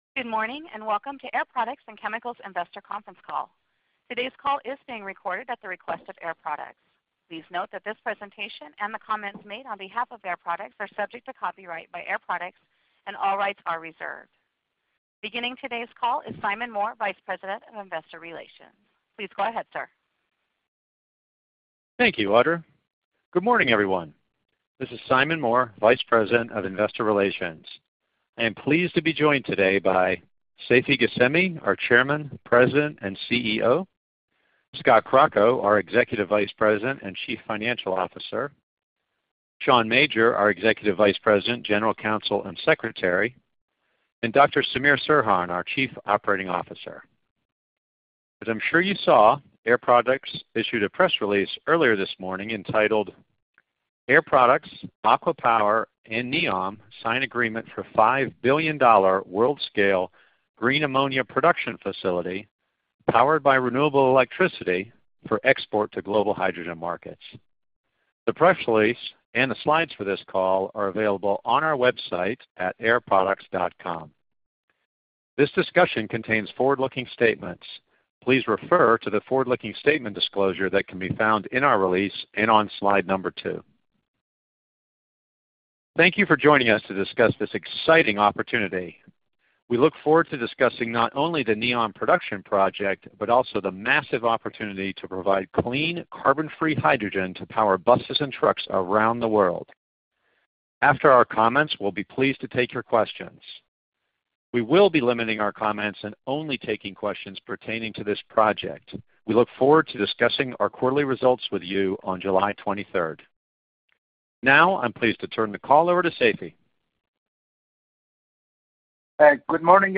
Air Products Conference Call on NEOM Project | Air Products